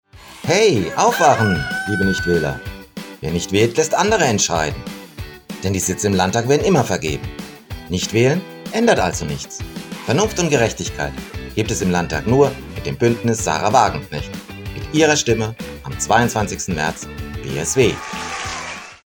Wahlwerbespots Hörfunk